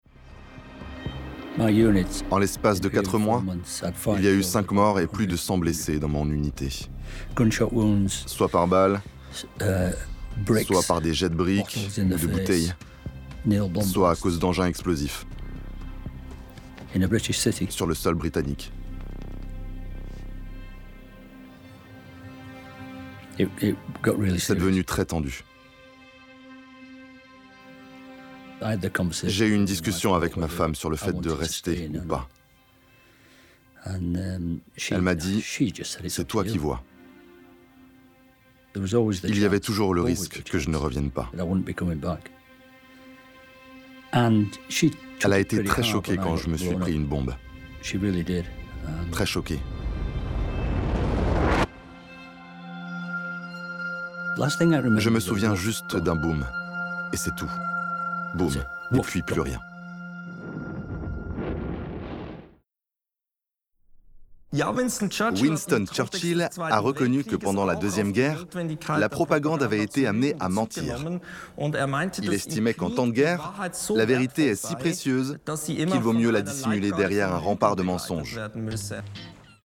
Voice Over - Combat Urbain
- Basse